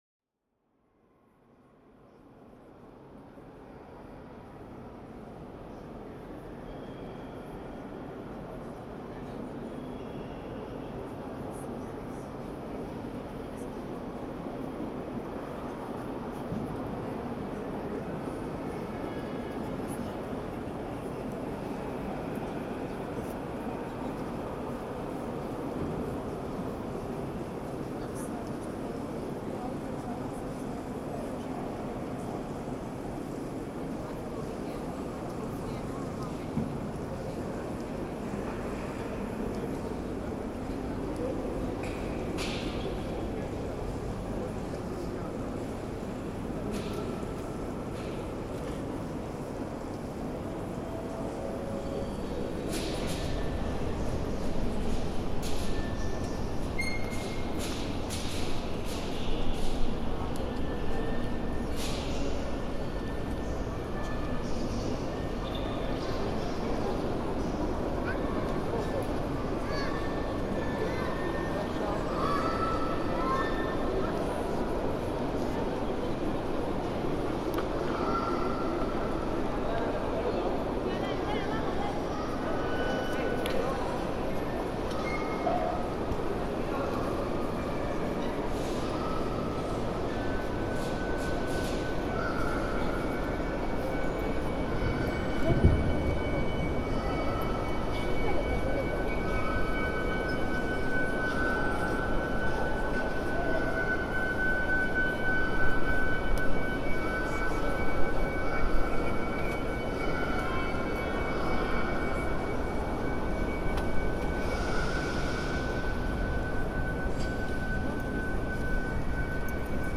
This 4½ minute recording captures the enormity of the space, as well as providing a stage on which small sounds can play.